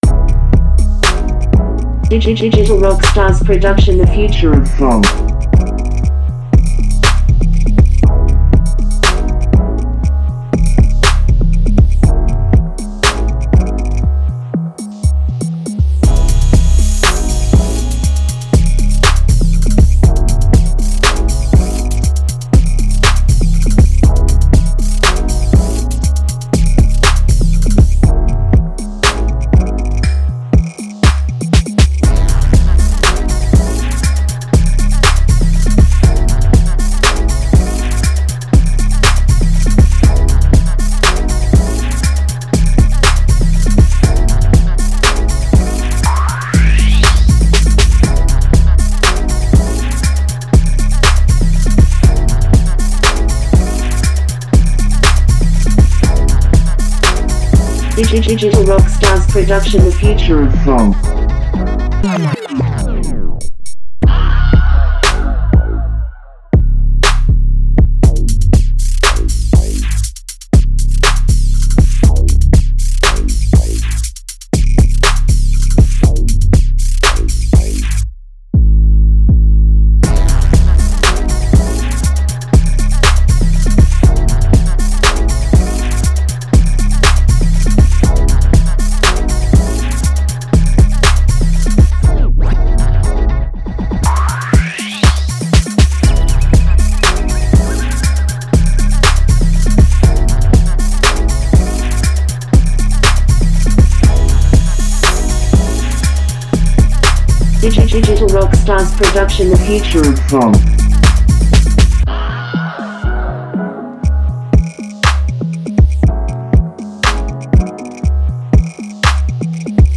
Tempo: 120BPM